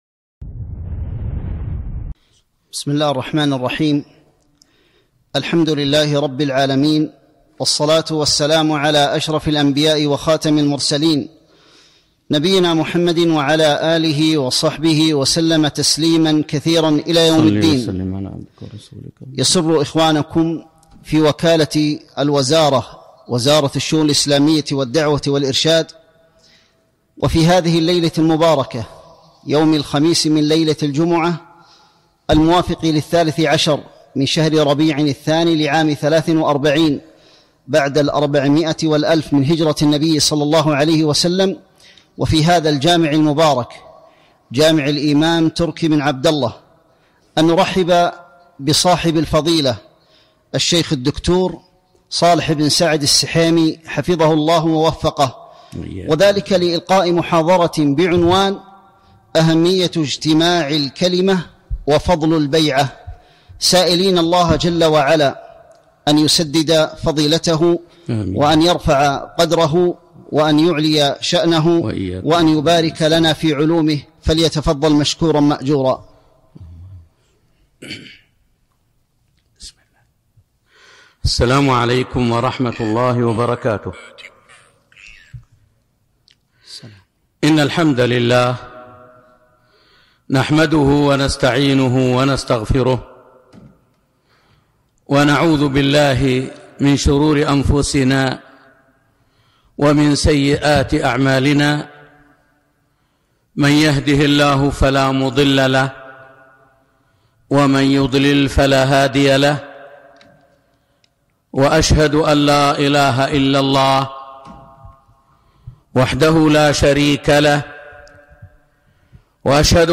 محاضرة - أهمية اجتماع الكلمة وفضل البيعة